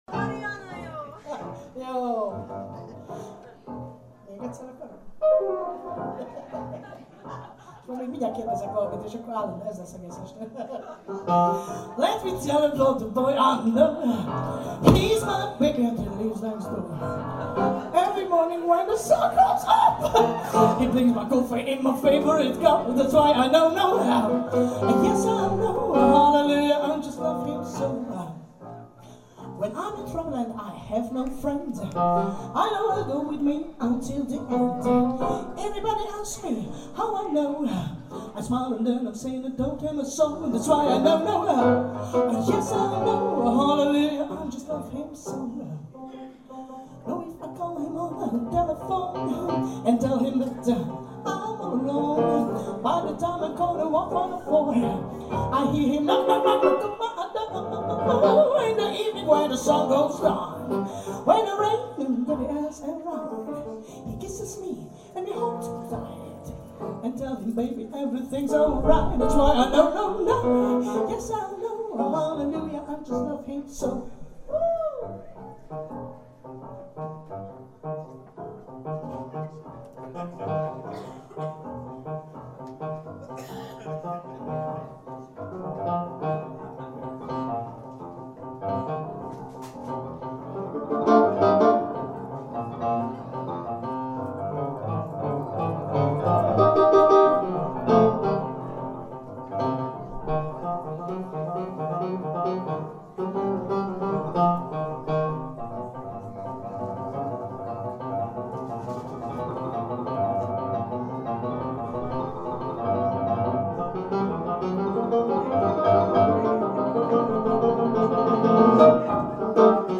Mindent is játszottak, (a huncut, játékos kupléktól, a popzenén és a jazzstandardokon át, Leonard Cohen  Hallelujaáig) közönséget is, kórusként meg dobszerkóként.